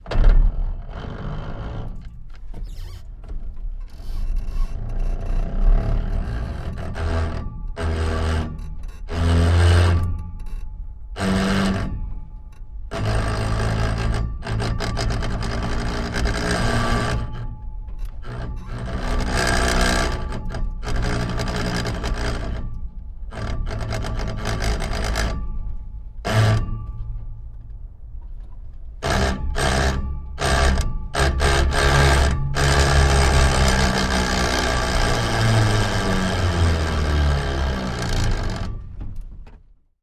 Car Transmission Manual; Starts Rolling And Goes Steady With Very Bad Gear Grinds, Winds Down To Stop At End, No Motor, Interior Perspective 2x